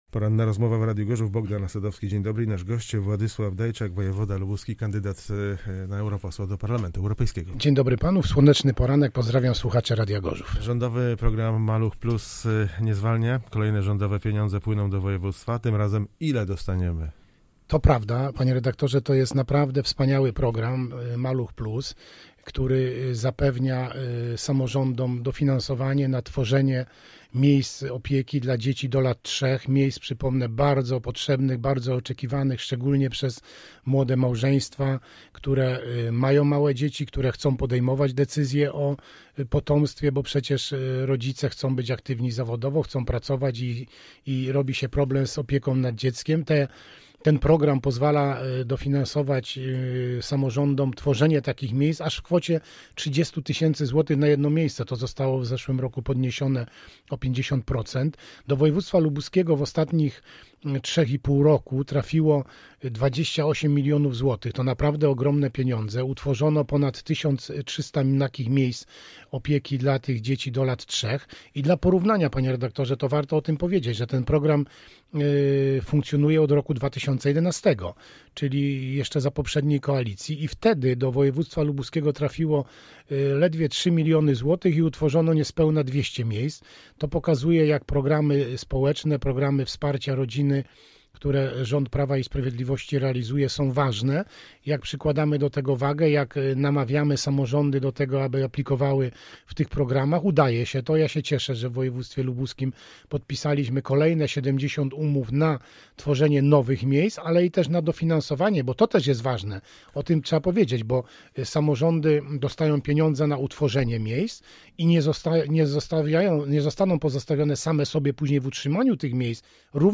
Gość na 95,6FM